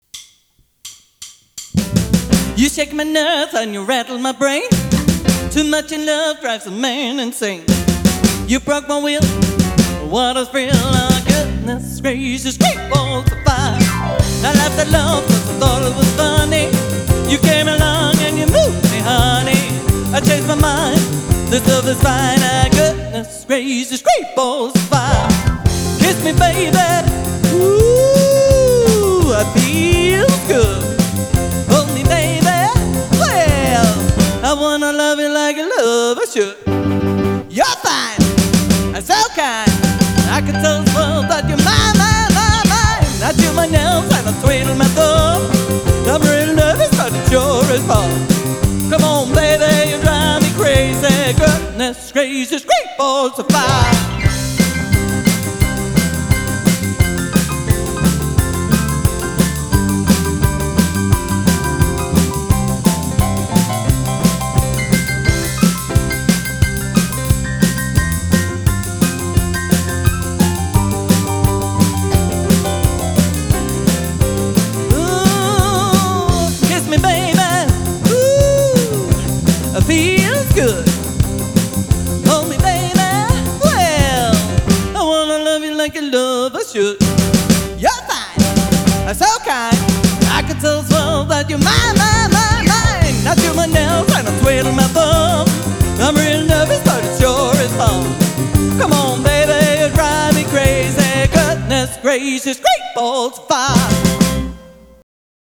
Festligt danse band, god musik, der får folk på dansegulvet.
Live musik:
• Allround Partyband